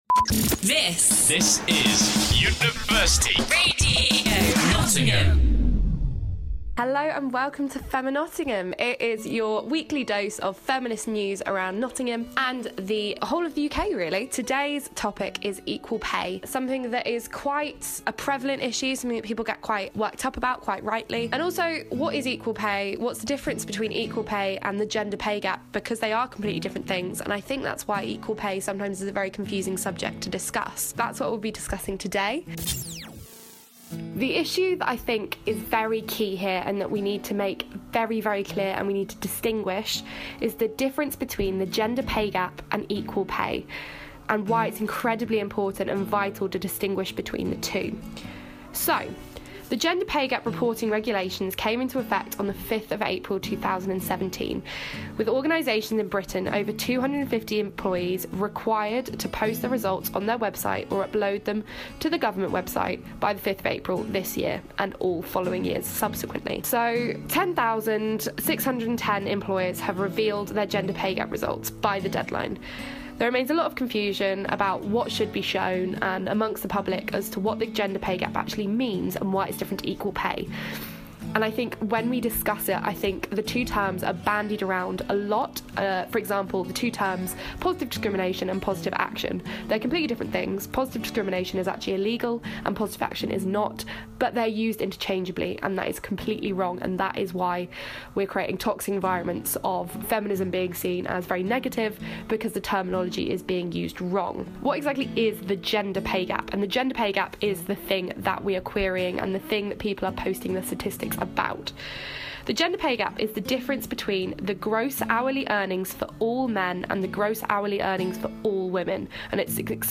Also, featuring an interesting interview with the charity 'Close the Gap', about their work in Scotland on women in the labour market.